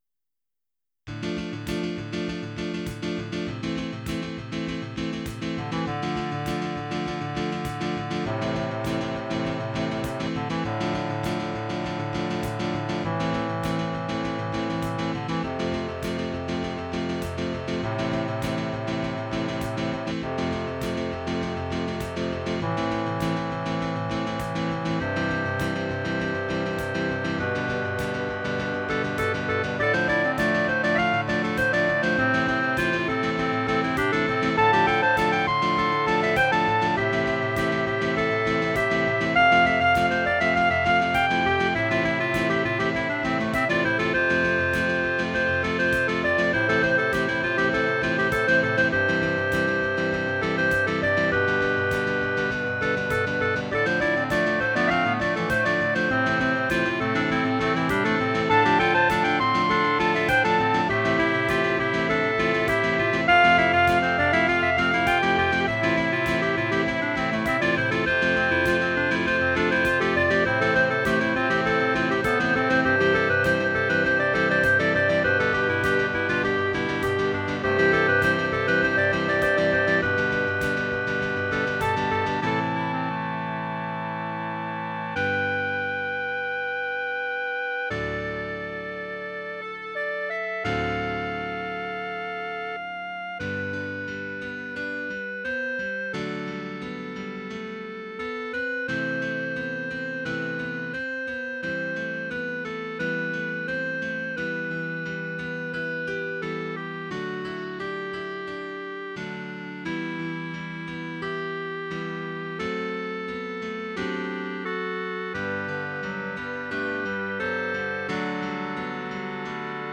Tags: Piano, Clarinet, Percussion
Title Clarinet Quintet Opus # 92 Year 2002 Duration 00:05:05 Self-Rating 3 Description This one just goes all over the place, but I like it a lot. mp3 download wav download Files: mp3 wav Tags: Piano, Clarinet, Percussion Plays: 2526 Likes: 0
092 Clarinet Quintet.wav